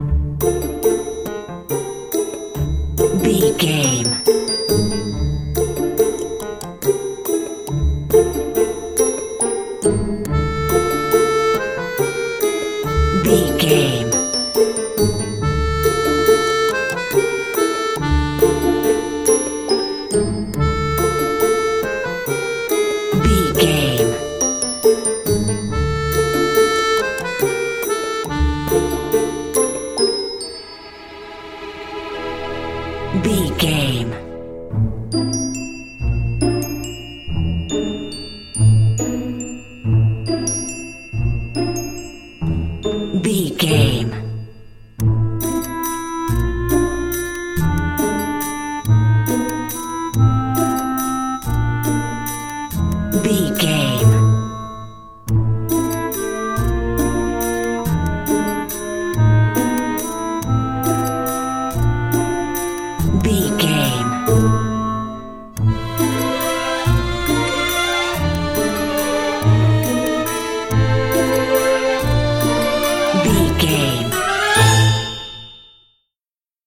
Ionian/Major
orchestra
strings
flute
drums
violin
circus
goofy
comical
cheerful
perky
Light hearted
quirky